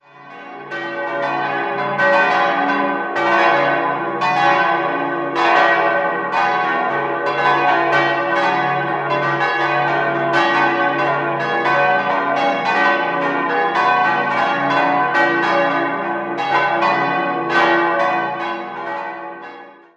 Nikolausglocke cis' 1.500 kg 140 cm 1948 Johann Hahn, Landshut Dreifaltigkeitsglocke e' 900 kg 118 cm 1948 Johann Hahn, Landshut Muttergottesglocke fis' 575 kg 103 cm 1948 Johann Hahn, Landshut Herz-Jesu-Glocke gis' 400 kg 92 cm 1948 Johann Hahn, Landshut Sakramentsglocke h' 200 kg 77 cm 1948 Johann Hahn, Landshut